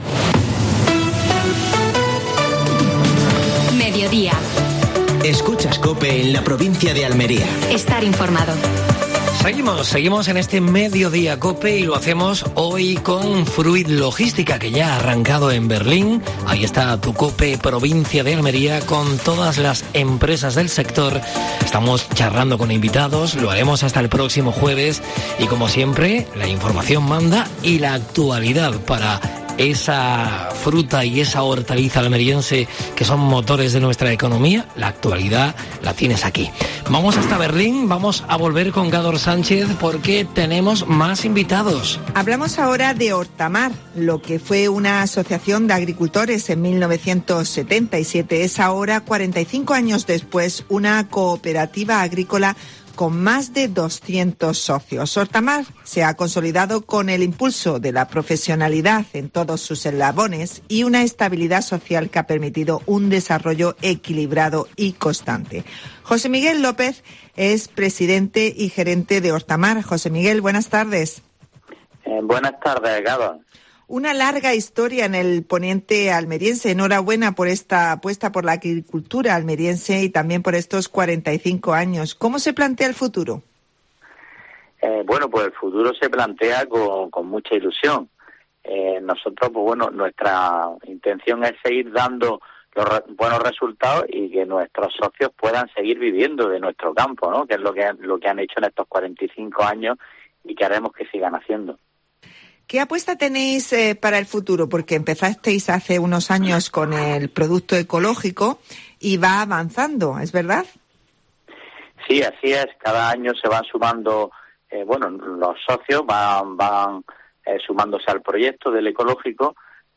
Actualidad en Almería. Primera jornada de Fruit Logística (Berlín). Entrevista